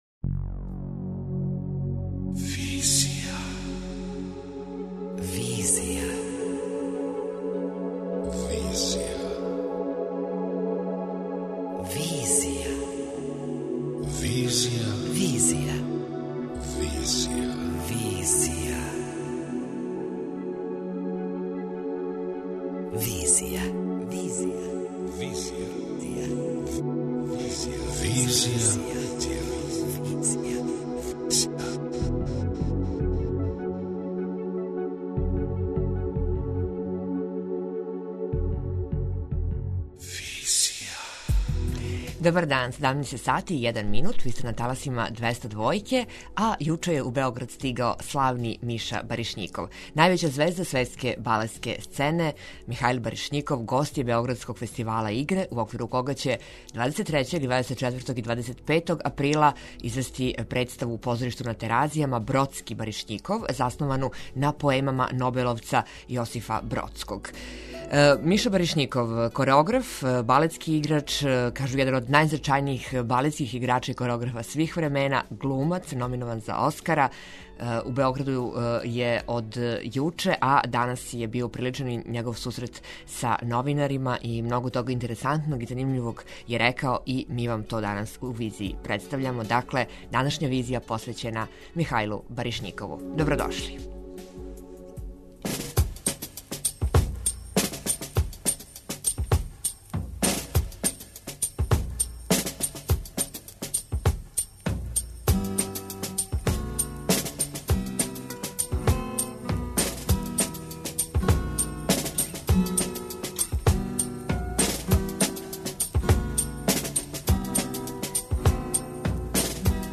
Интервју: Михаил Баришњиков - играч, кореограф и глумац